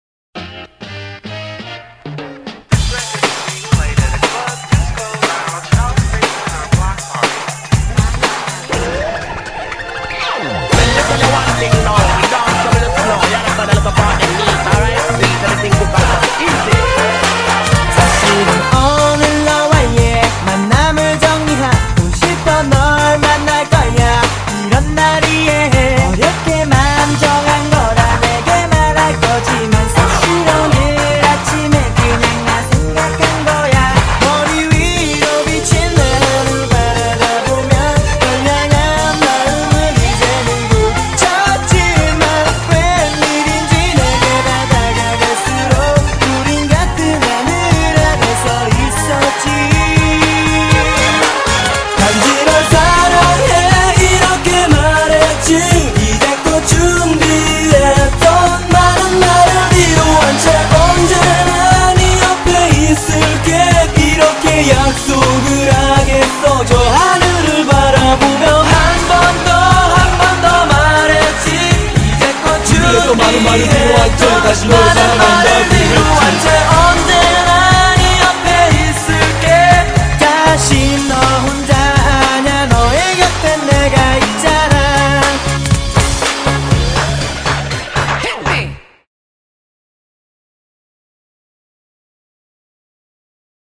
BPM120--1
Audio QualityPerfect (High Quality)